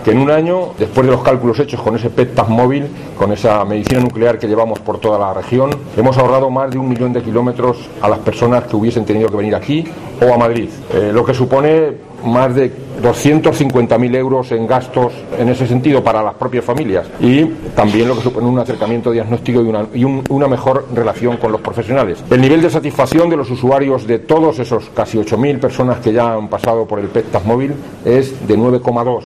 Jesús Fernández, consejero sanidad Castilla-La Mancha